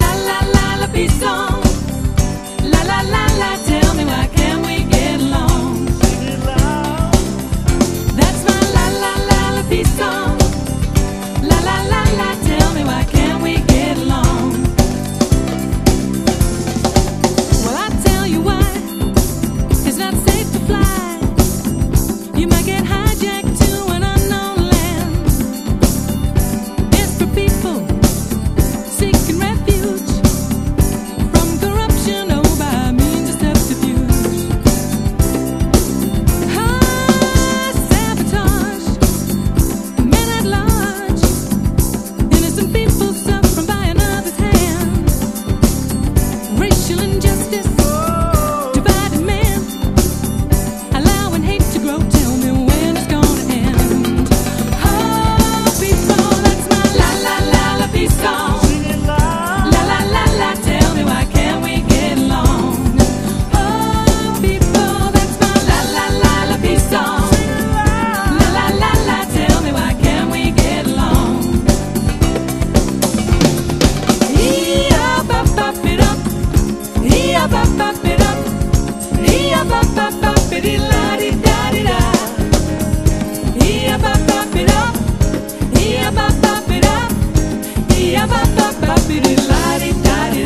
NEO-ACO/GUITAR POP / NEO MOD / BLUE EYED SOUL / NEW WAVE
トゥットゥットゥルル・スキャットもスバラしい粘っこいキラメキ・ブルーアイド・ソウル